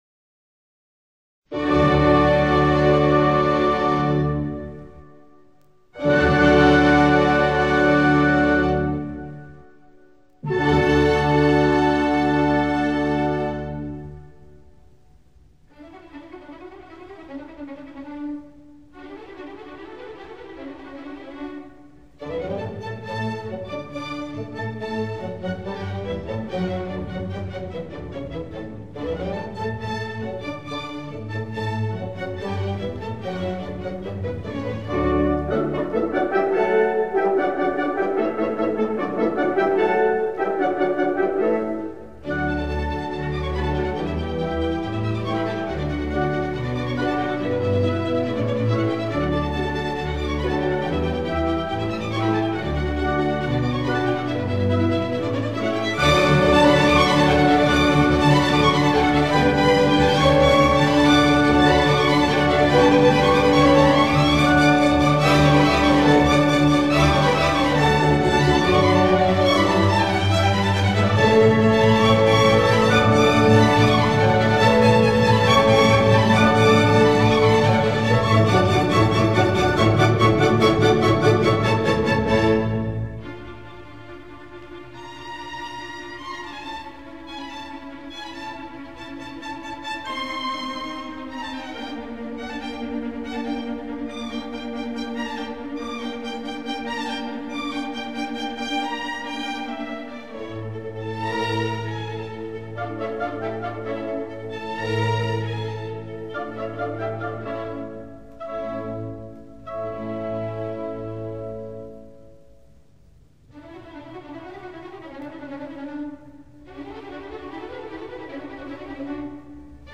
Le mariage secret - Ouverture